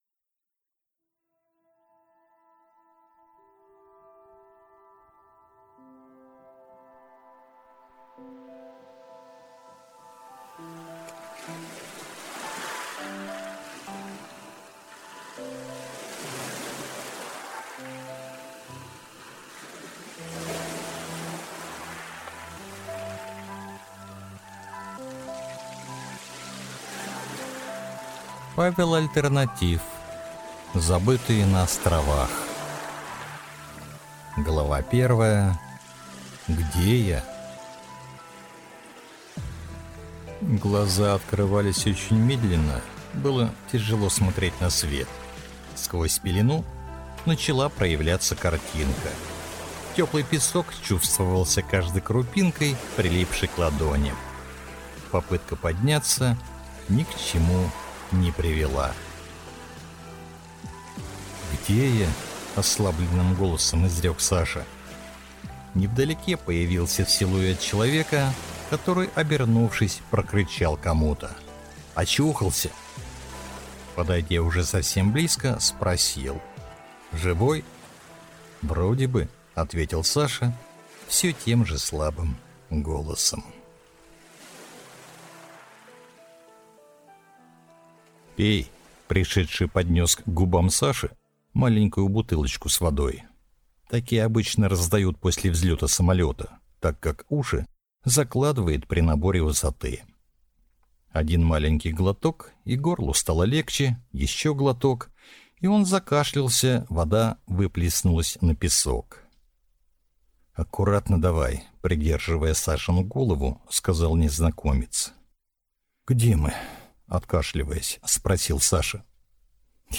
Аудиокнига Забытые на островах | Библиотека аудиокниг